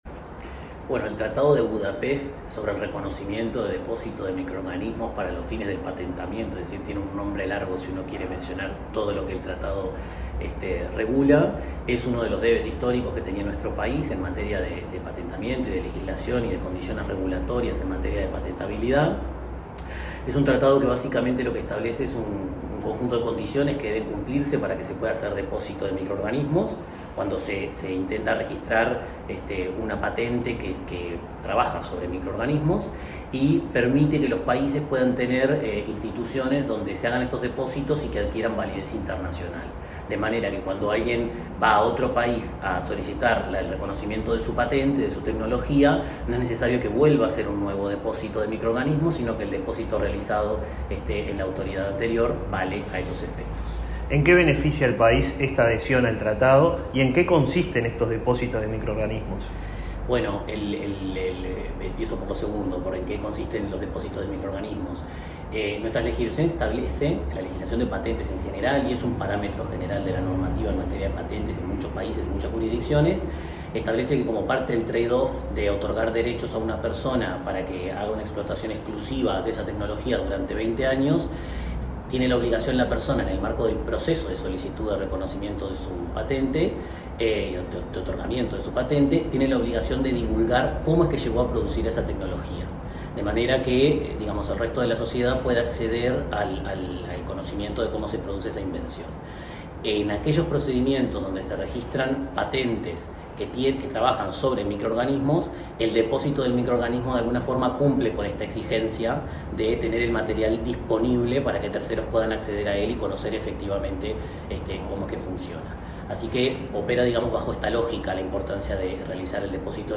Entrevista al director técnico de la Propiedad Industrial del MIEM, Santiago Martínez
El director técnico de la Propiedad Industrial del Ministerio de Industria, Energía y Minería (MIEM), Santiago Martínez, dialogó con Comunicación